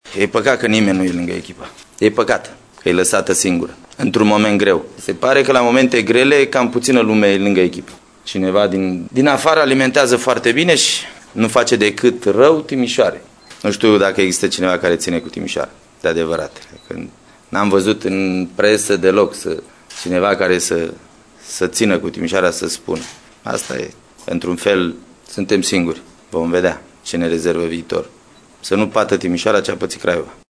Ascultați o declarație a lui Hagi din 28 aprilie 2006, când se plângea de situația de la Timișoara.